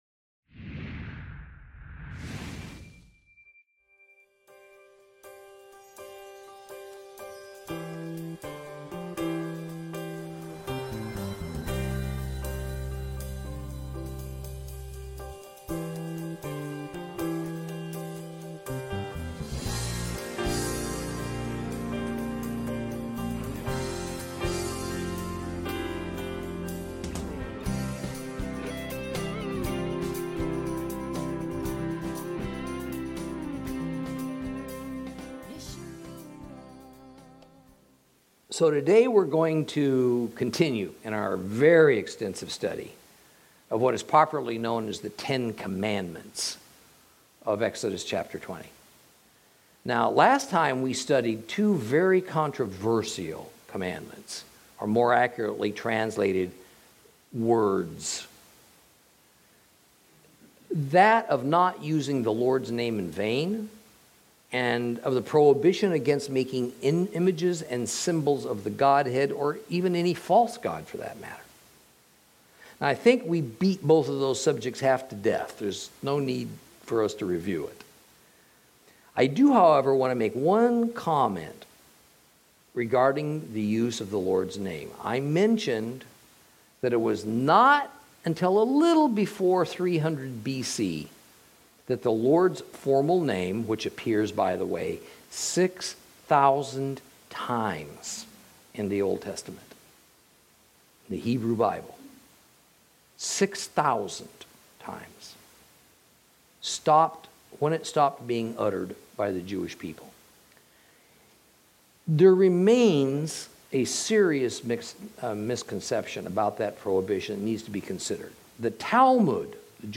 Lesson 19 Ch20 - Torah Class